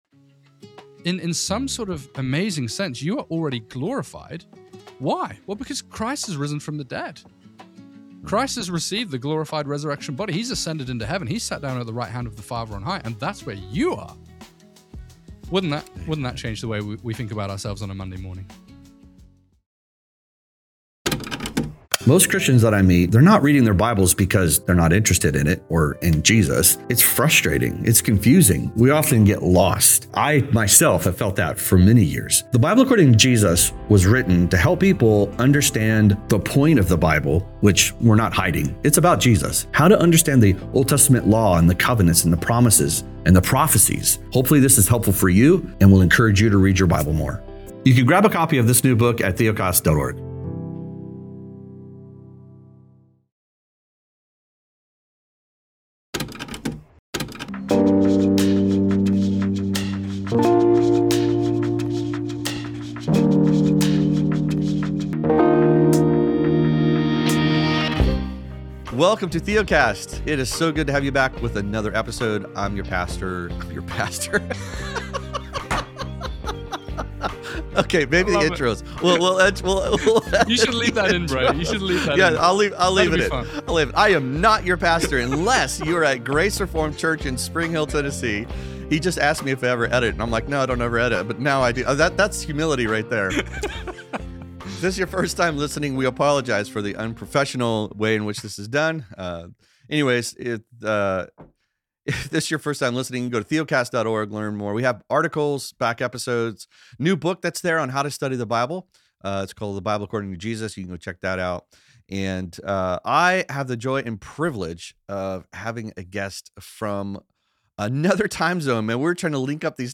You will hear how this doctrine reshapes assurance, sanctification, and even the pull toward Rome or Eastern Orthodoxy by offering real transcendence without trading Christ for rituals. If reading the Bible feels confusing or heavy, this conversation helps you see Scripture as a guide that leads you to Jesus and fills your joy.